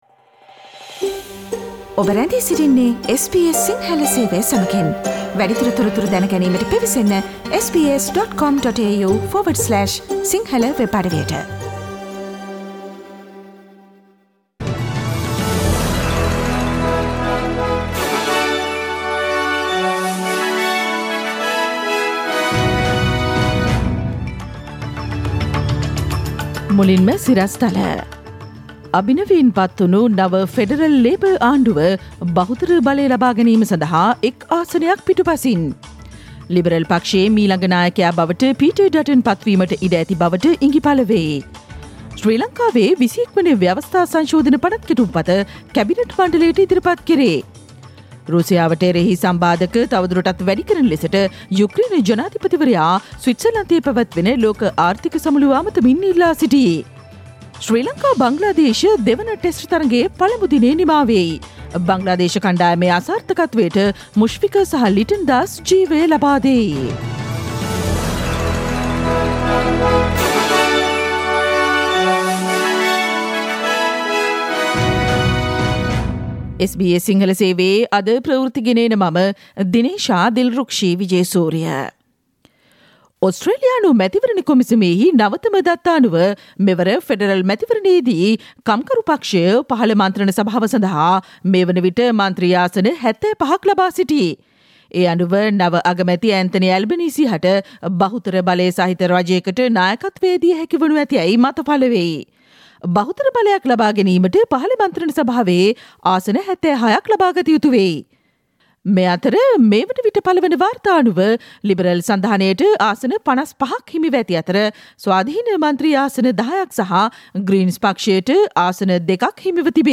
Click on the speaker icon on the image above to listen to the SBS Sinhala Radio news bulletin on Tuesday 24 May 2022.